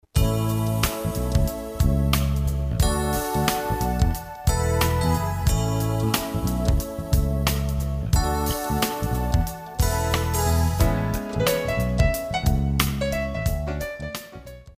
Contemporary 96d